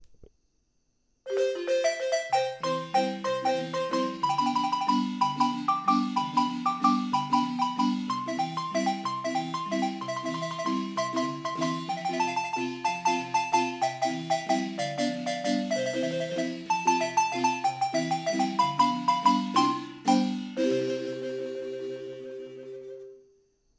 Marimba GUANACASTE